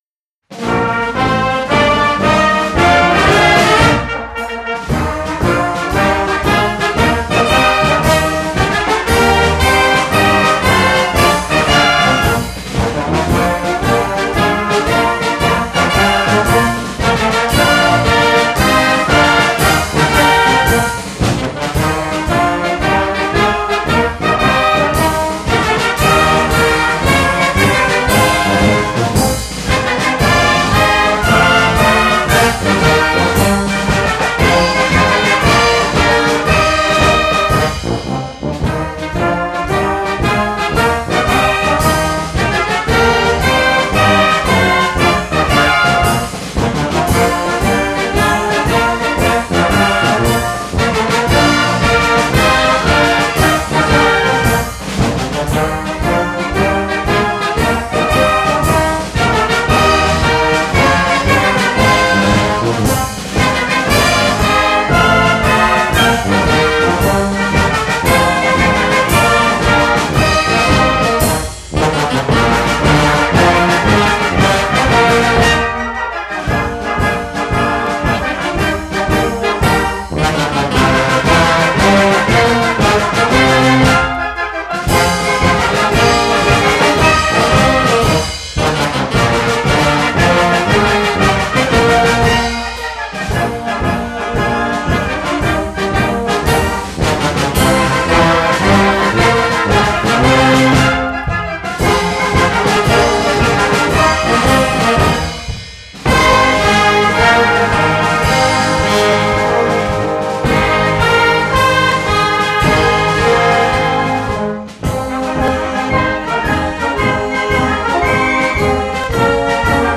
Orkiestra Dęta Okulice